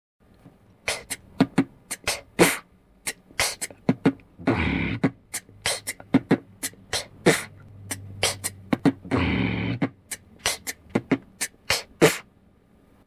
"битбоксерский вариант"
b--kch--b-b-b-kch-/-b--kch--b-b-b--kch - версия с хаммингом;